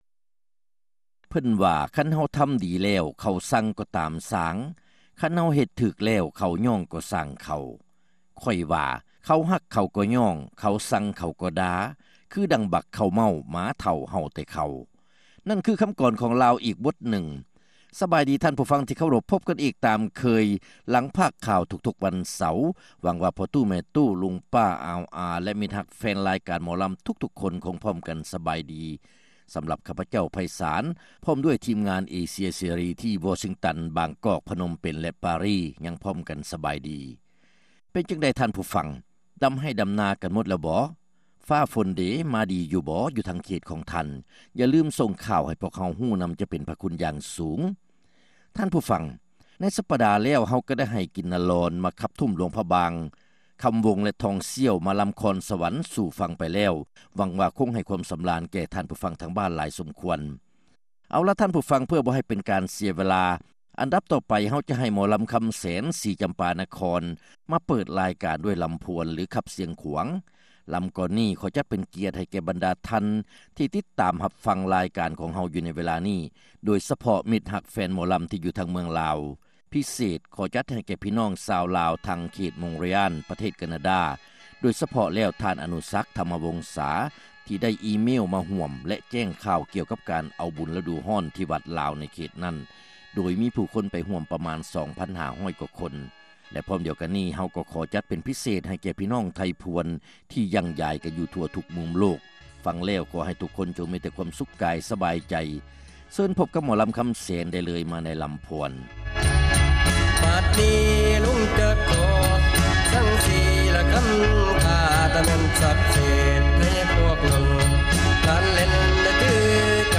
ຣາຍການໜໍລຳ ປະຈຳສັປະດາ ວັນທີ 5 ເດືອນ ສິງຫາ ປີ 2006